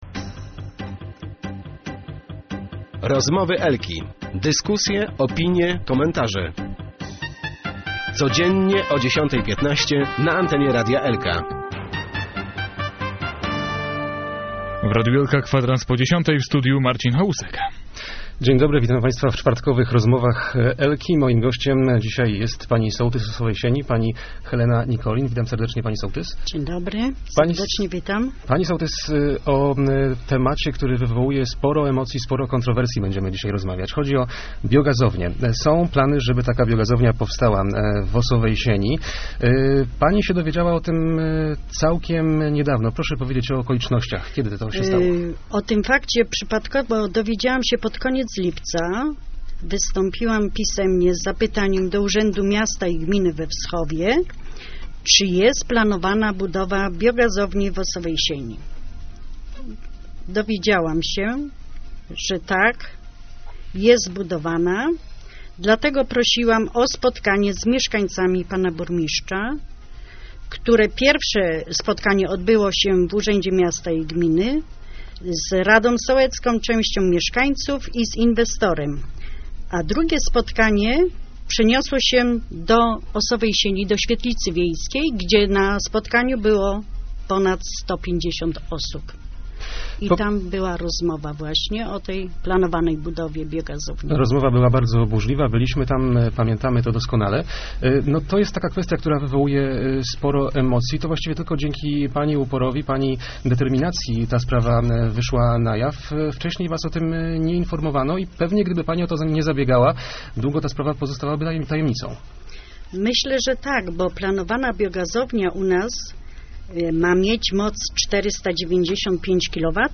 Nie spoczniemy w walce przeciwko biogazowni w Osowej Sieni - mówiła w Rozmowach Elki sołtys Helena Nikolin.